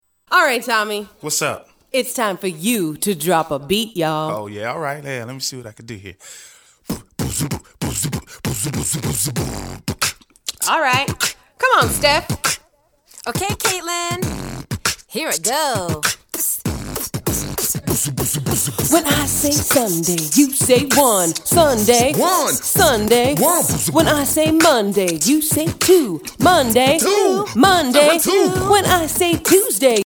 Learning the Days of the Week beatbox style